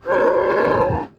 sounds_bear_02.ogg